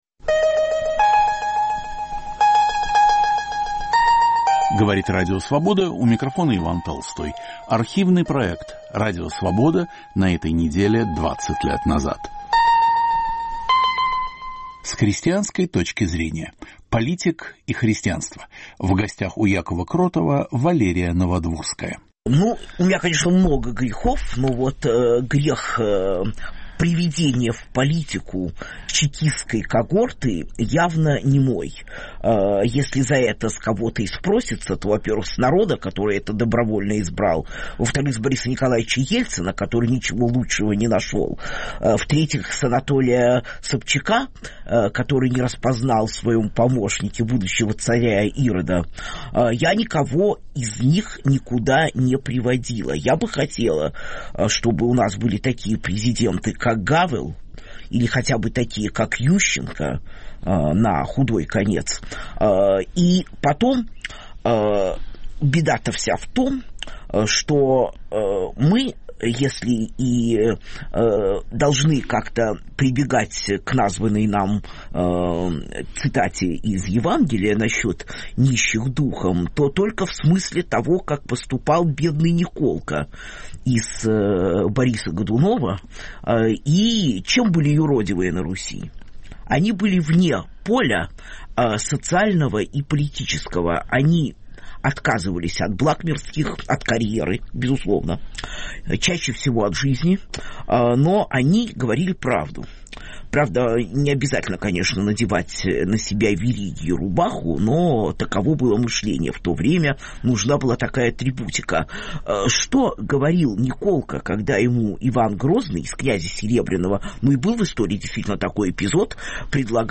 С христианской точки зрения. Политик и христианство. В гостях у Якова Кротова Валерия Новодворская
Автор и ведущий Яков Кротов.